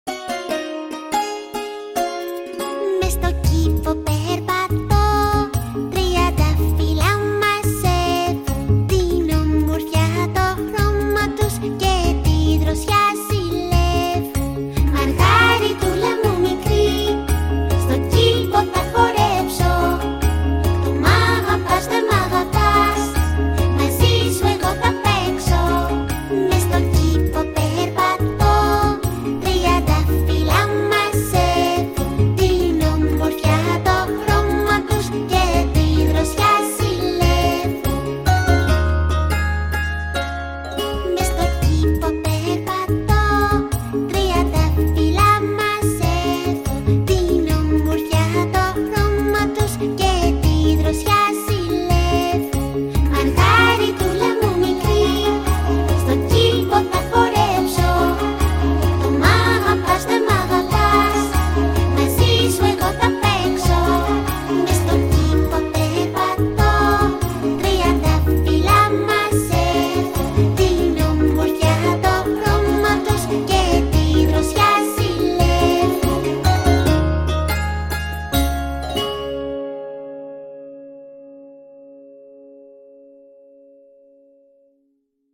Παιδικό τραγούδι